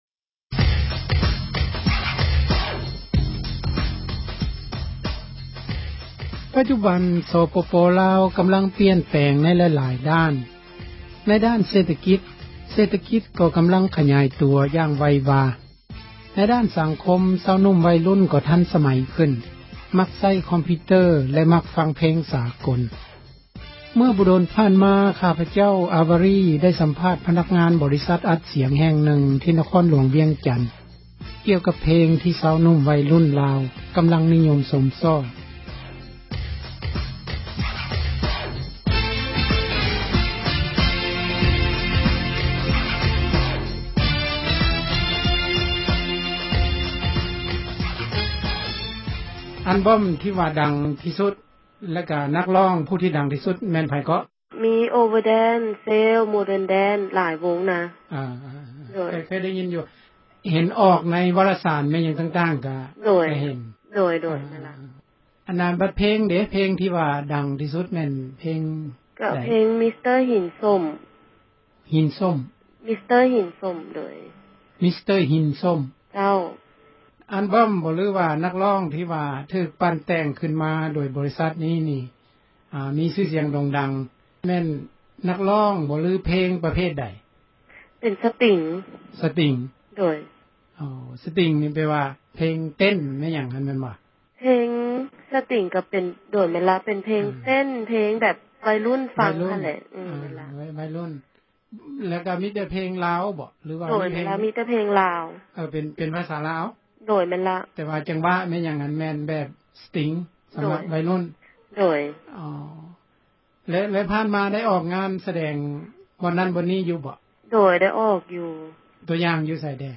ໄດ້ສຳພາດ ພະນັກງານ ບໍຣິສັດ ອັດສຽງ ແຫ່ງນຶ່ງທີ່ ນະຄອນຫລວງ ວຽງຈັນ ກ່ຽວກັບເພງ ທີ່ຊາວໜຸ່ມ ໄວລຸ້ນລາວ ກໍາລັງ ນິຍົມກັນຢູ່.